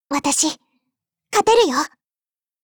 Cv-10205_warcry_9.mp3 （MP3音频文件，总共长2.7秒，码率323 kbps，文件大小：106 KB）